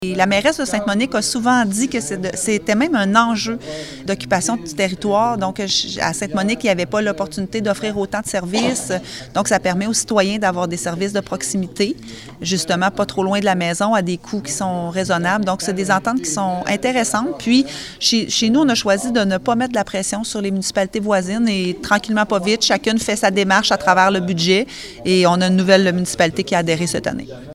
Alors que cet enjeu a créé des tiraillements ces dernières années entre certaines villes-centres et des municipalités périphériques,  la mairesse Geneviève Dubois a de nouveau souligné la bonne entente de Nicolet avec ses voisins.